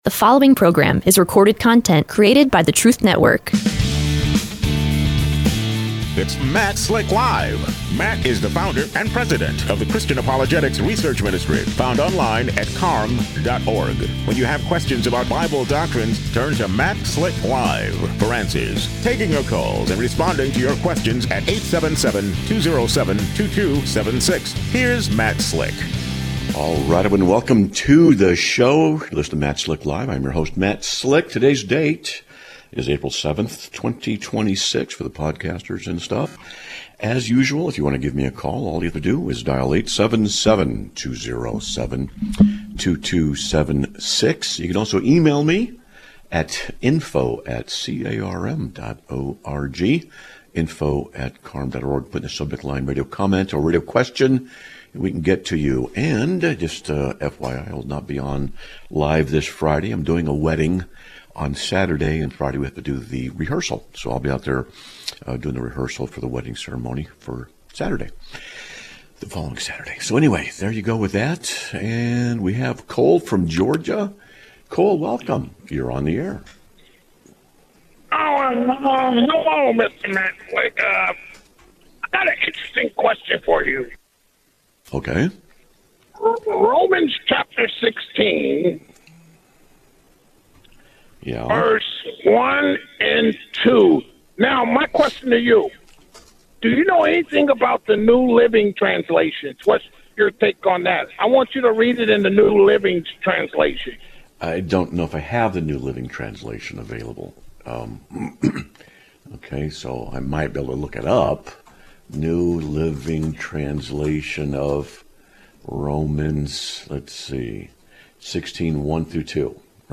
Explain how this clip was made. Live Broadcast of 04/7/2026